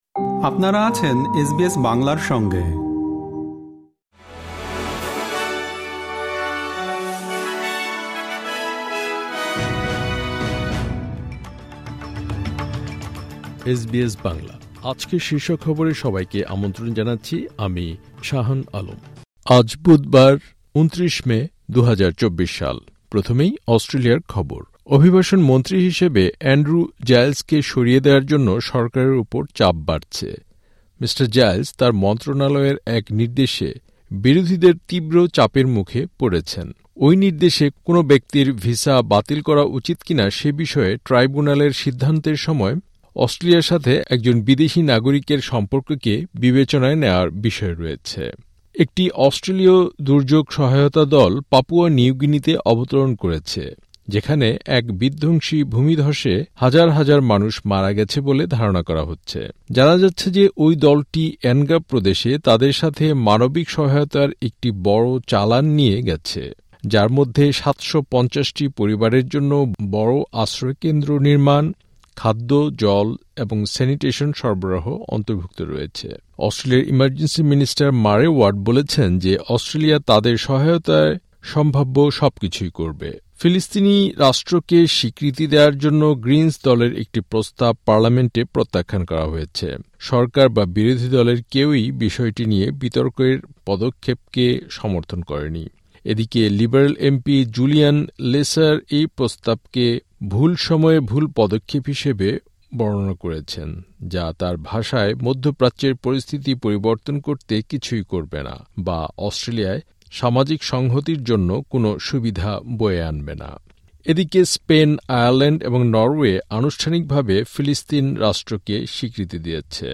এসবিএস বাংলা শীর্ষ খবর: ২৯ মে, ২০২৪